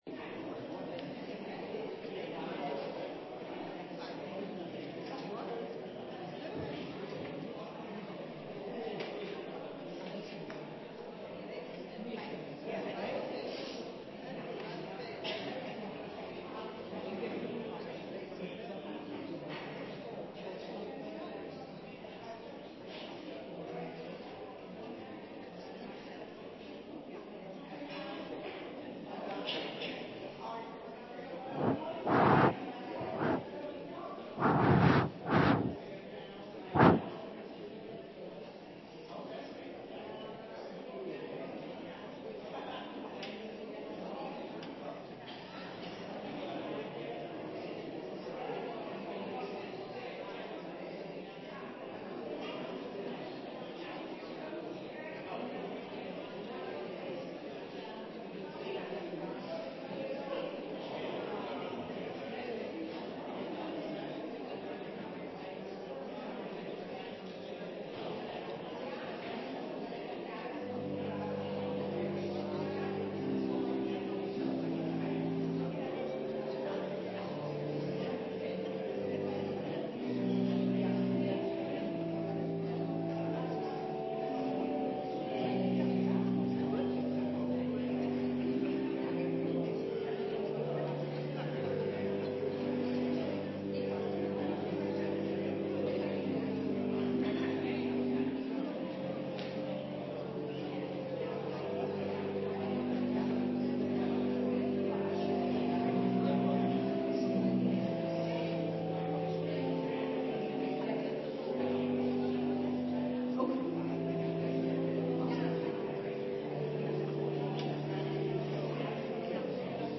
Opnames uit de Ontmoetingskerk.